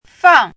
chinese-voice - 汉字语音库
fang4.mp3